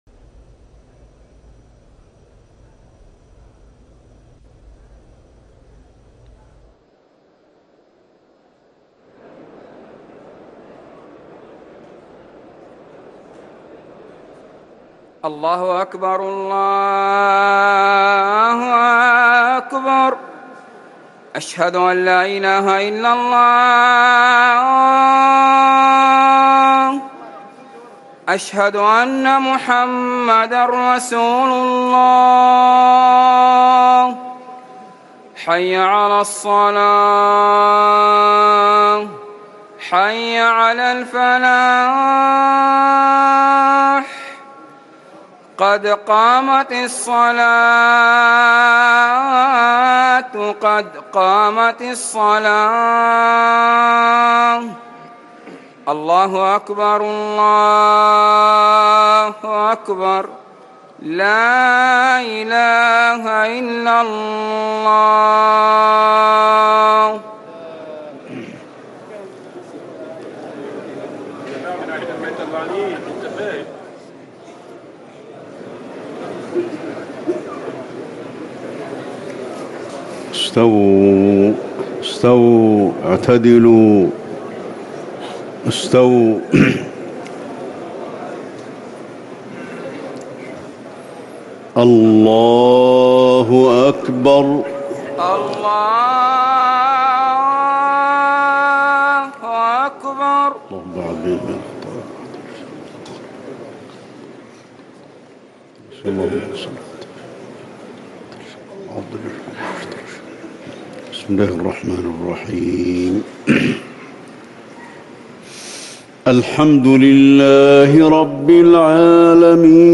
صلاة المغرب 3-9-1440هـ سورتي الإنفطار و الإخلاص | Maghrib 8-5-2019 prayer Surah Al-Infitar and Al-Ikhlas > 1440 🕌 > الفروض - تلاوات الحرمين